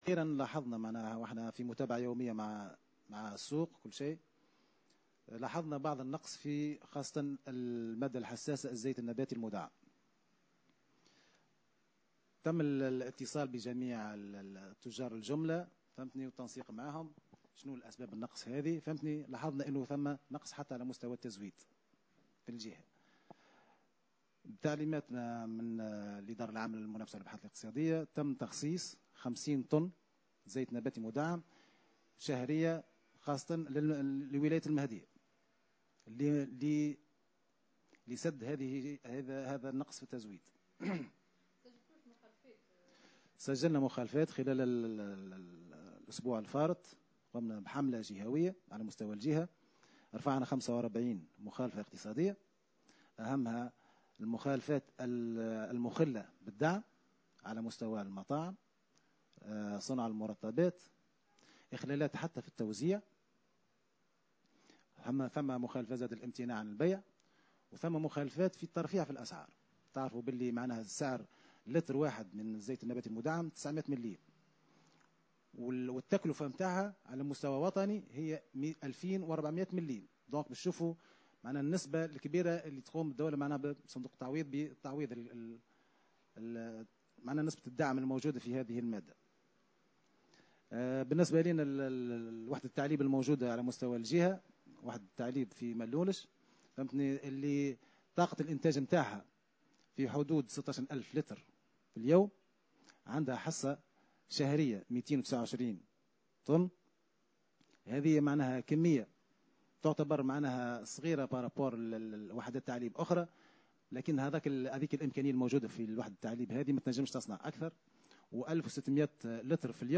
أكد المدير الجهوي للتجارة بالمهدية، الحبيب نصري في تصريح لمراسلة "الجوهرة أف أم" بالجهة أنه تم خلال الفترة الماضية تسجيل نقص في التزود بمادة الزيت النباتي المدعم في الجهة.